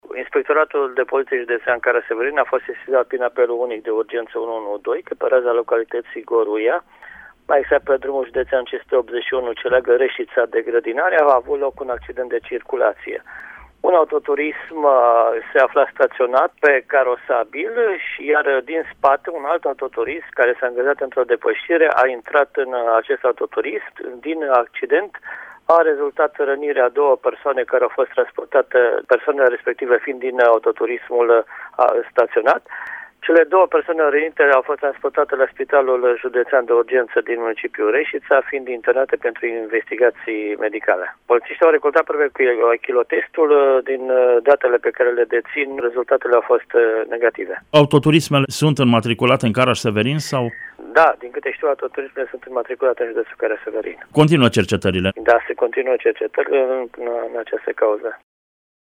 declaraţia